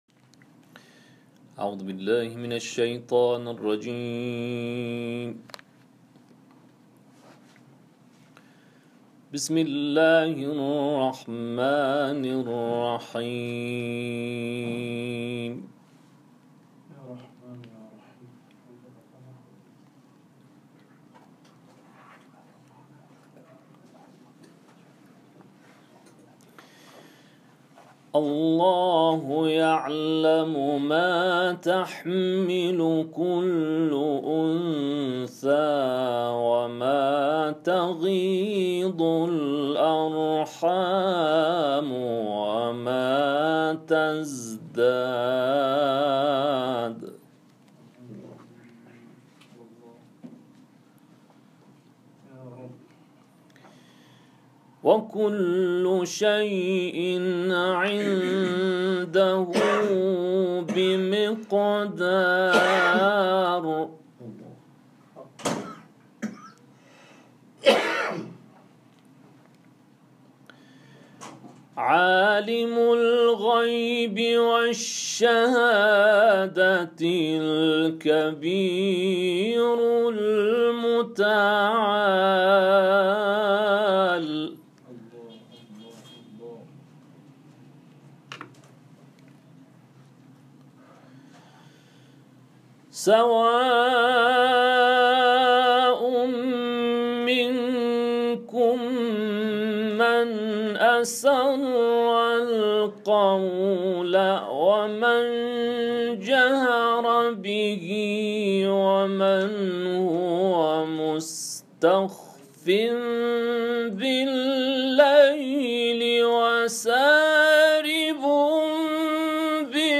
تلاوت در کانال‌های قرآنی/